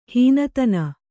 ♪ hīna tana